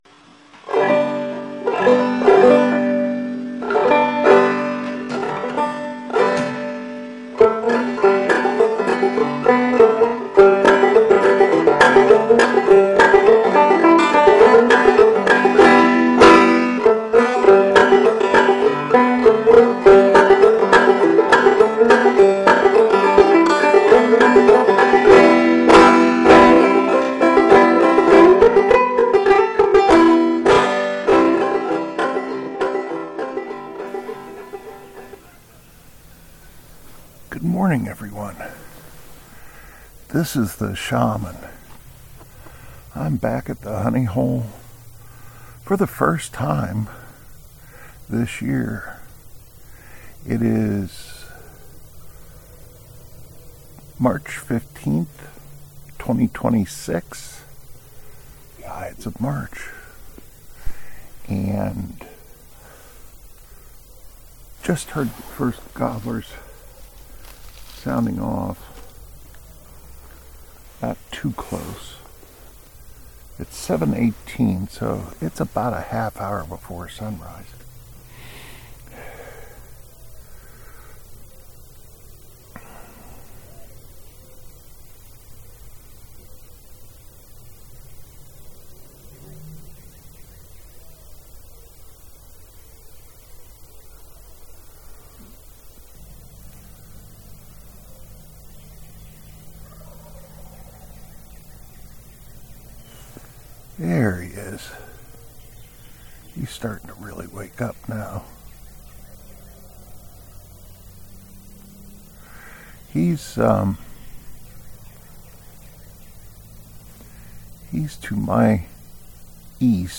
This was my first trip out to the Honey Hole this year. The gobblers and hens are just starting to warm up.